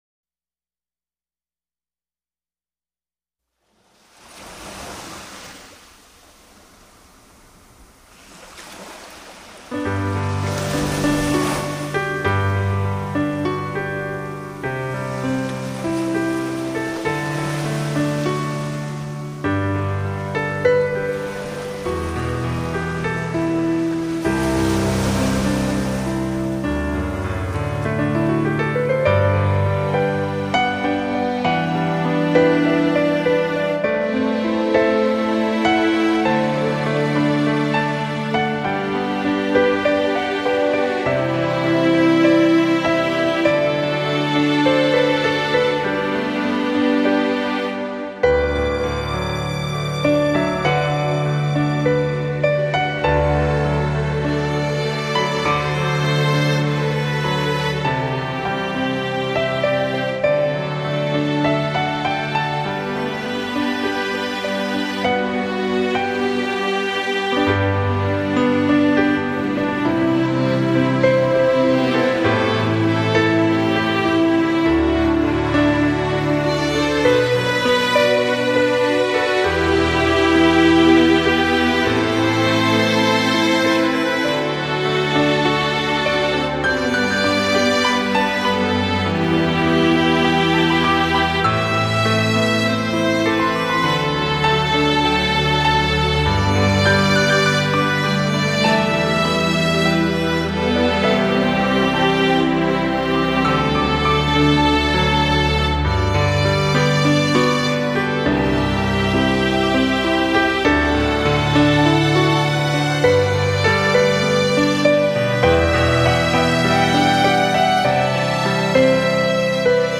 这是一张知性兼具感性的音乐专辑－－海浪和着鲸声，律动的节奏和着悠扬的曲调，演释出鲸豚传奇的一生和牠们细腻的心声。